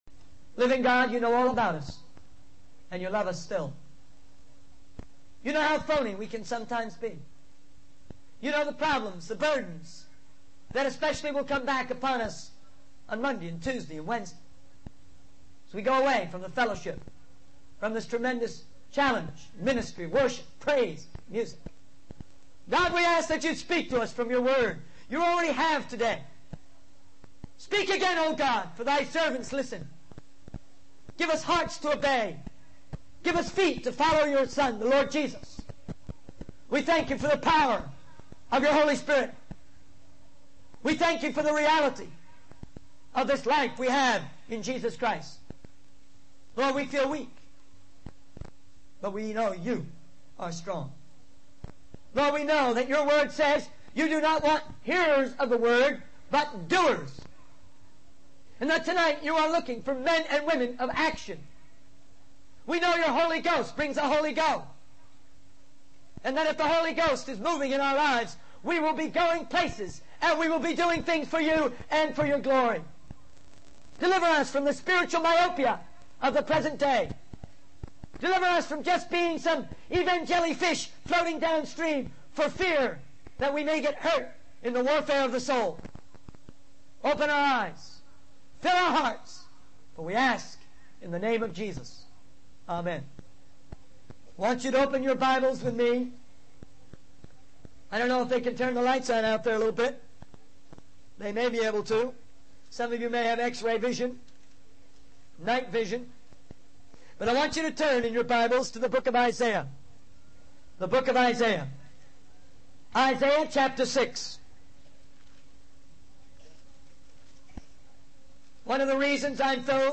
In this sermon, the speaker emphasizes the importance of preaching the gospel to all people, as commanded in Acts 1-8.